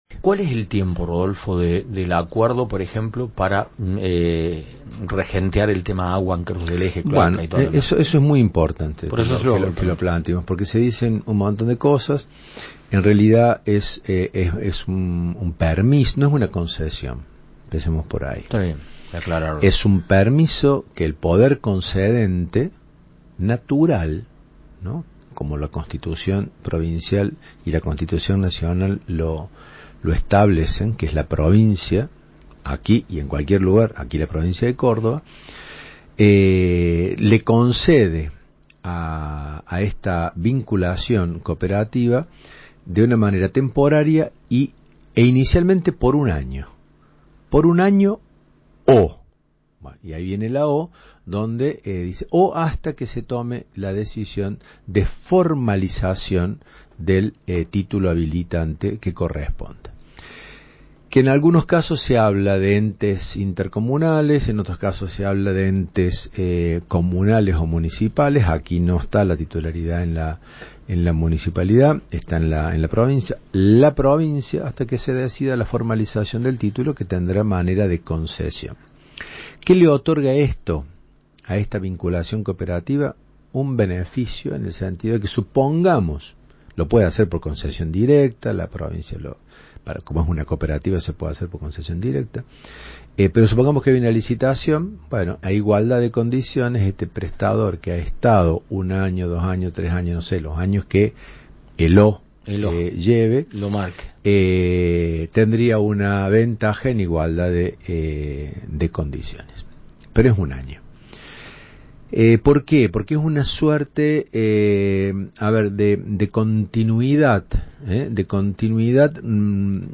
En diálogo con Cadena Centro FM